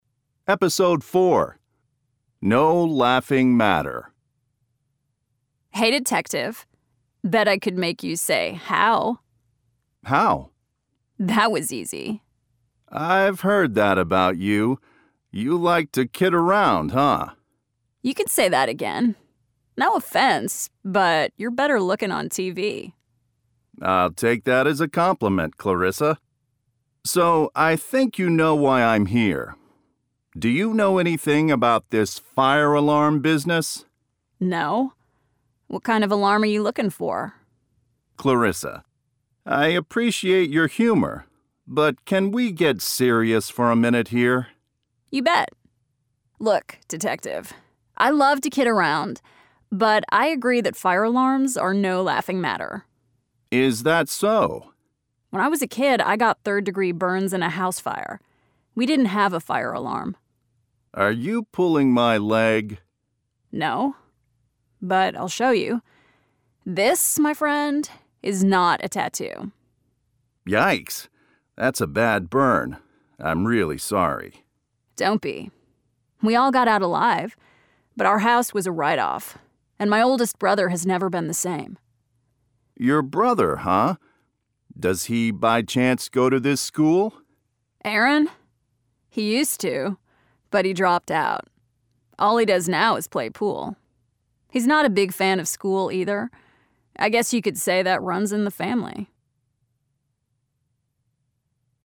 Each episode features a dialogue between the famous Detective Bossley and one of his prime suspects. 04.
Episode_04_Dialogue.mp3